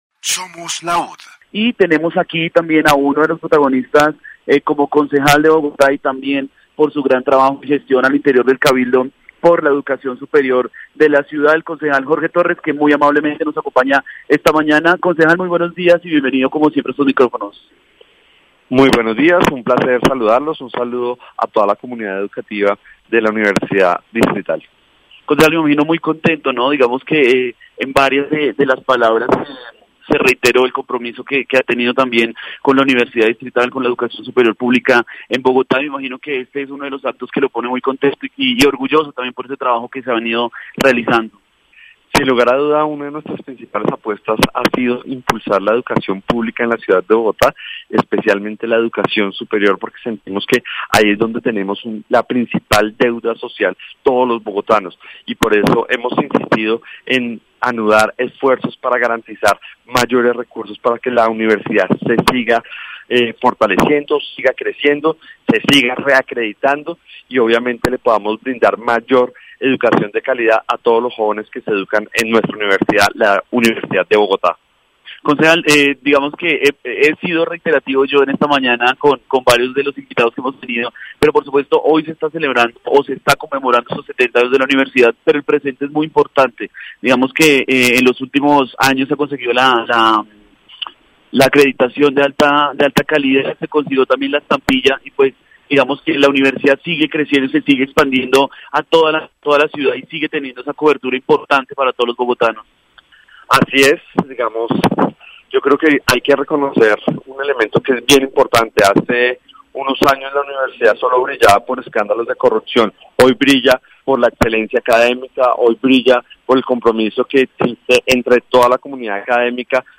🇺🇸 Jorge Torres and the Strengthening of the Distrital University The Bogotá Councilman, Jorge Torres, is interviewed as part of the commemoration of the 70th anniversary of the Distrital University Francisco José de Caldas.
Universidad Distrital Francisco Jose de Caldas -- 70 años , Universidad Pública-- Crecimiento Infraestructura , Politica Educativa-- Bogotá-- Siglo XXI , Programas de Radio , Torres,Jorge, Concejal de Bogotá -- Entrevista